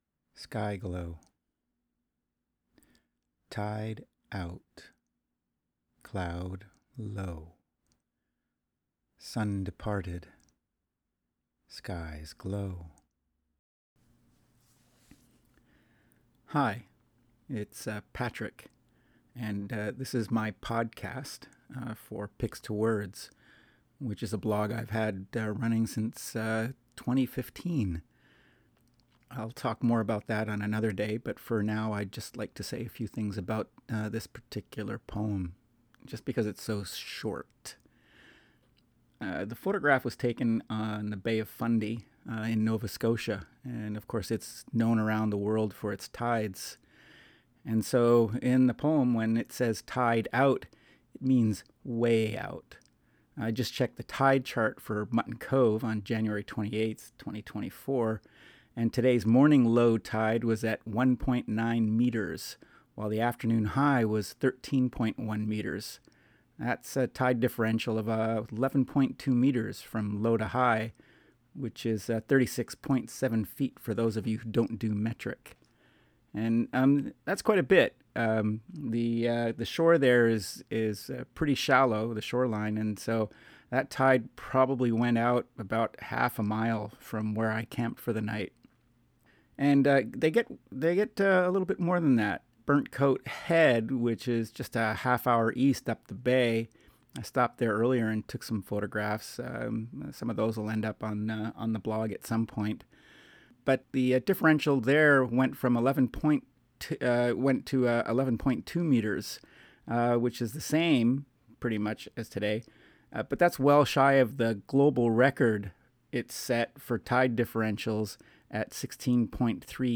Fujifilm X-T4, Landscape, Poetry